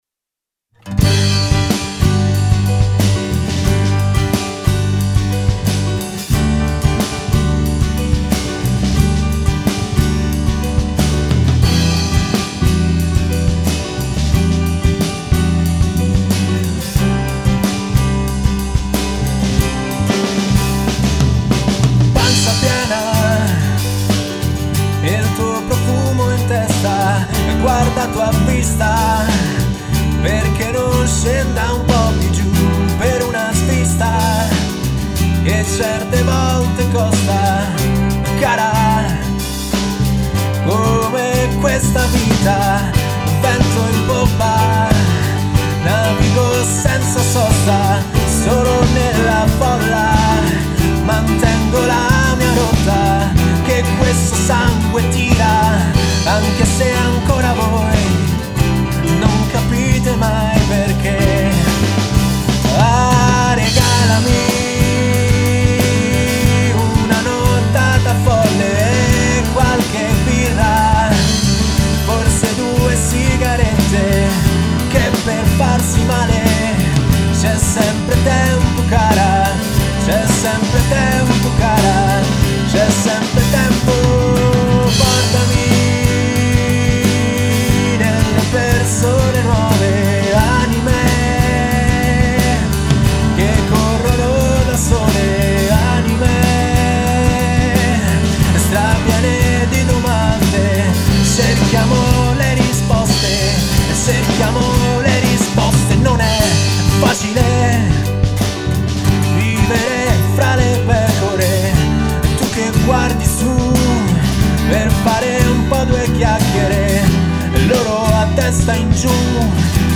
voce e chitarre
voce e piano